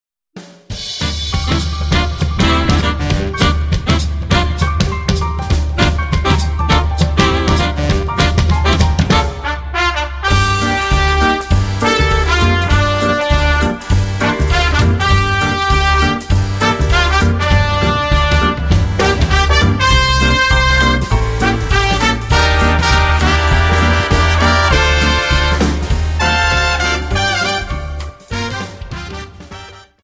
Dance: Quickstep Song